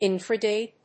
音節in・fra dig 発音記号・読み方
/ínfrədíg(米国英語)/